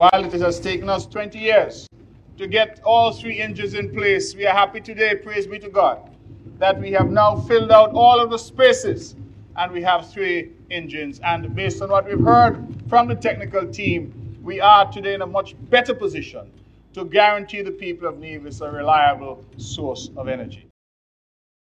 The Commissioning Ceremony of a new 3.8 Megawatt Wӓrtsilӓ Generator was held on Tuesday, March 15th, at the NEVLEC (Nevis Electricity Company Limited) Power Station, in Prospect, Nevis.
Mr. Brantley, who is also the Minister of Public Utilities said the island is in a better position:
Premier-Brantley.mp3